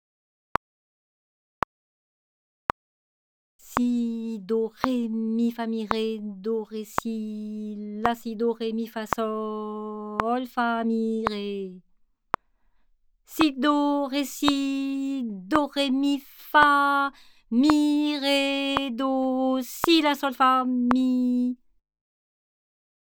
Rythme 01